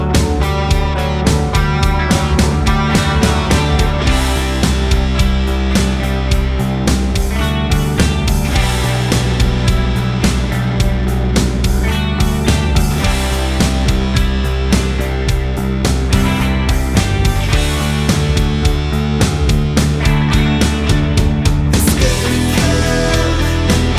no Backing Vocals Rock 5:52 Buy £1.50